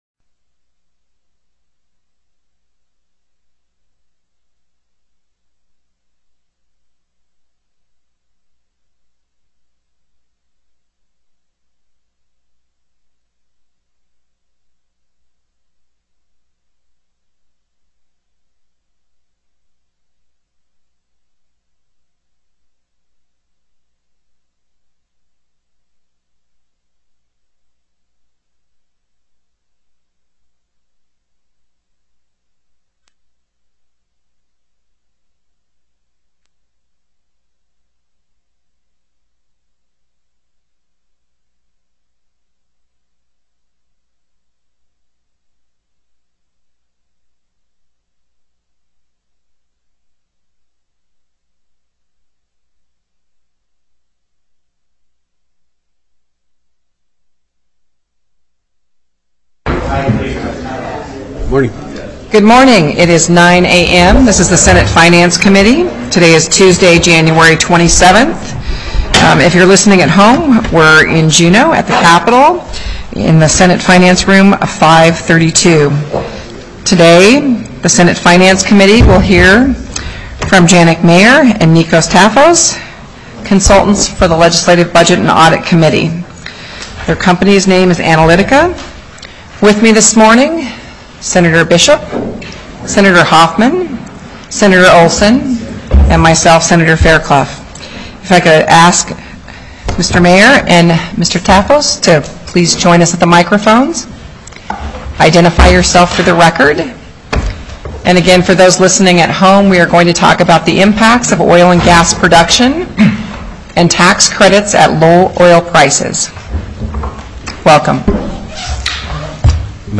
01/27/2015 09:00 AM Senate FINANCE
Presentation: Oil and Gas Tax Credits